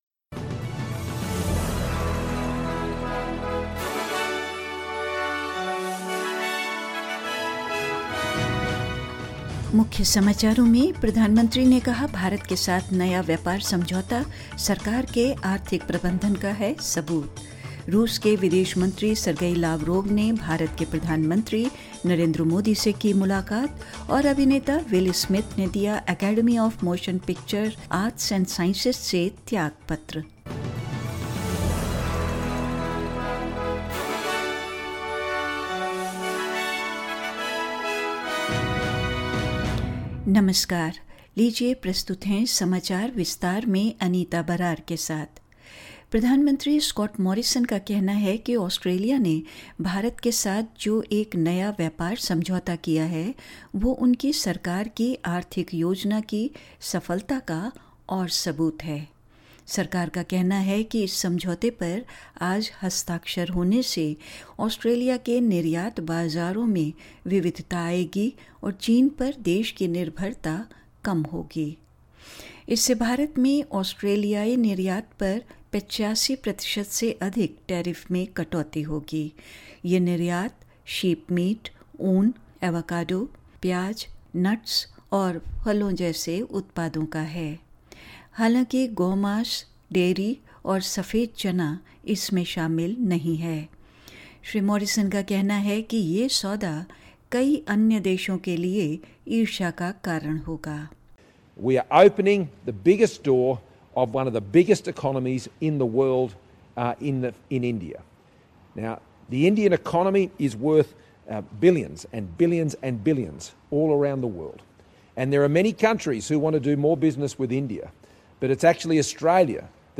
In this latest Hindi bulletin: The Prime Minister says a new trade deal with India is further proof of his government's economic mangement; Actor Will Smith resigns from Hollywood's Academy of Motion Picture Arts and Sciences after slapping Oscar's host Chris Rock; Russian Foreign Minister Sergey Lavrov meets with Indian PM Narendra Modi in Delhi and more news.